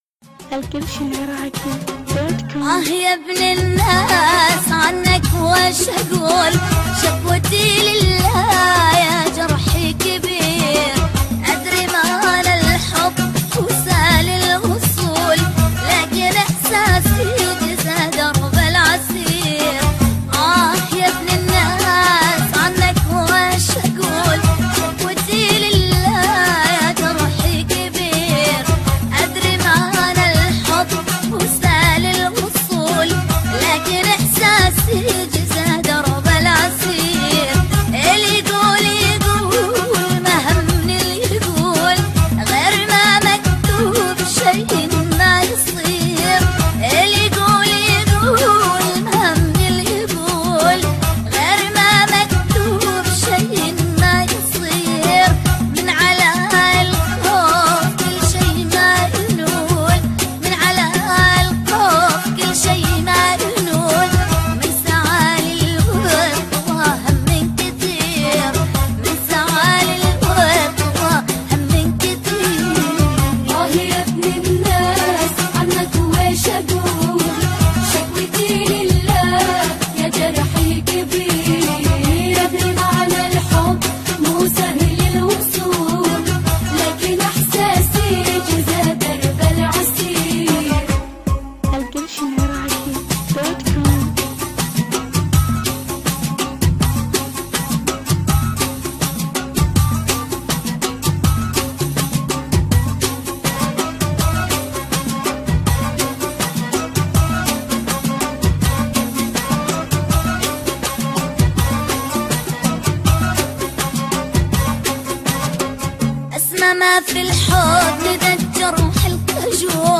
اغاني عربيه
مسرع